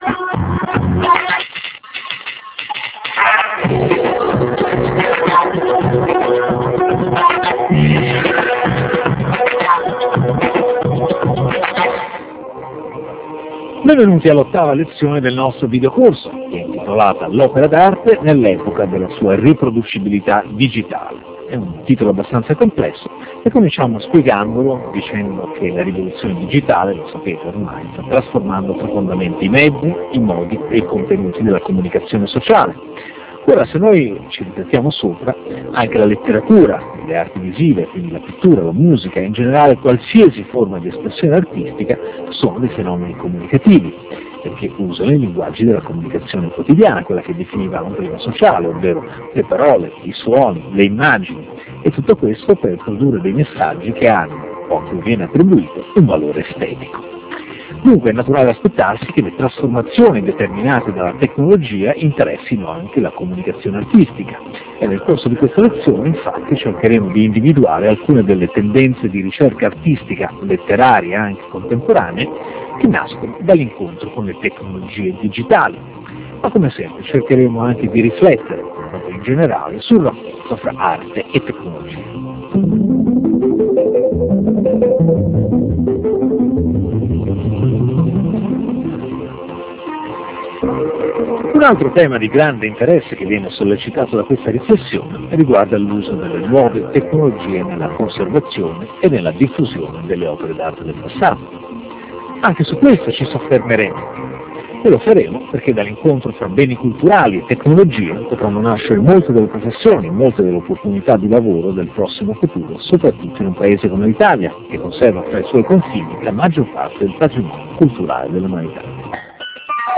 Lezione n. 08